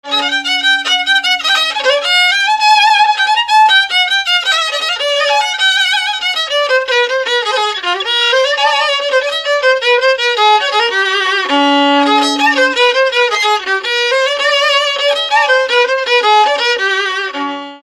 Dallampélda: Hangszeres felvétel
Erdély - Alsó-Fehér vm. - Nagymedvés
hegedű Műfaj: Szökő Gyűjtő